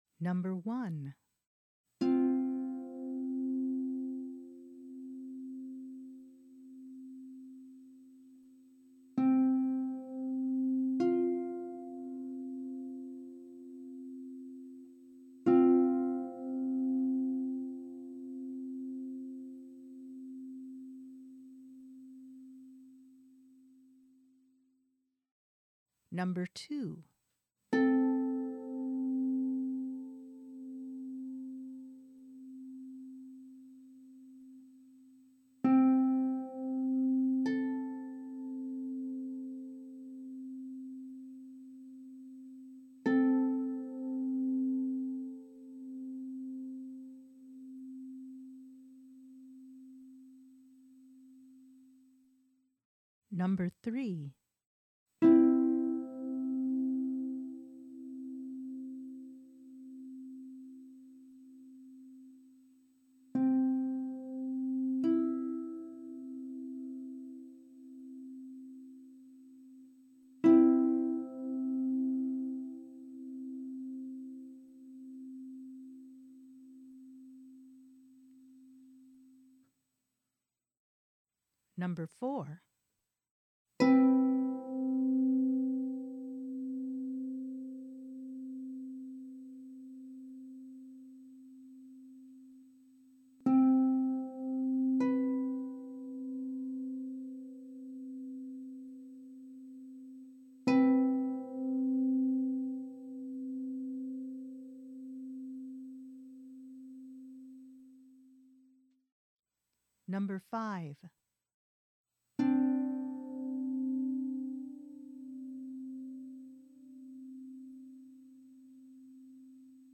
Audio #1 Listen to each interval and write down what you hear, 2nd, 3rd, 4th etc. I'll play each interval followed by each individual note, then the interval again for each one.
intervals3.mp3